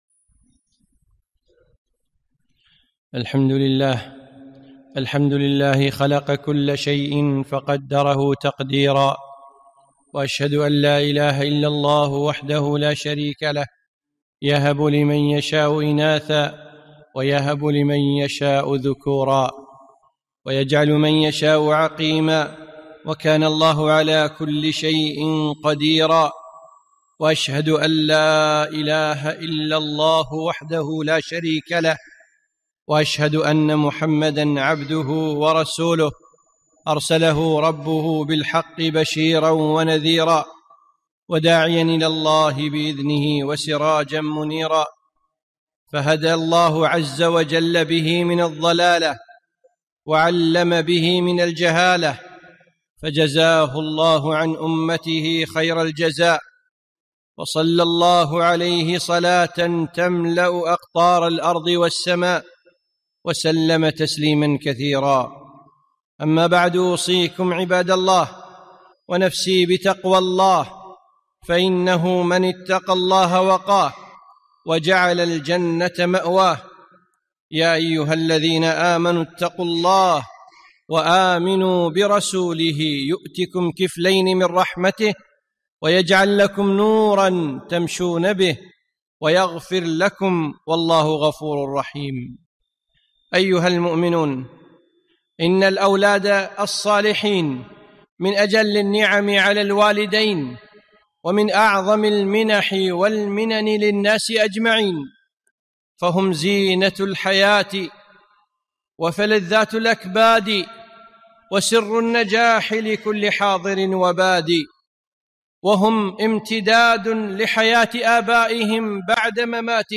خطبة - رعـايـة الأولاد